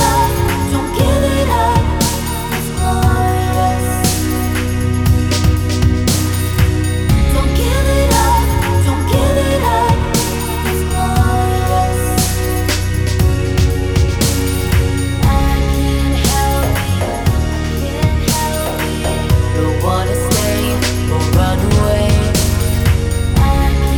no Backing Vocals Indie / Alternative 4:17 Buy £1.50